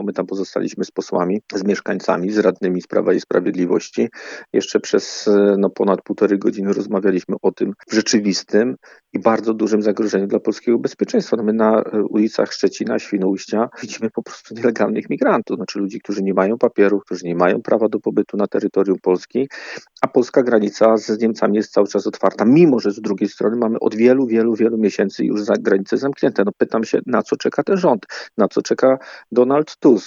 Mówi poseł Zbigniew Bogucki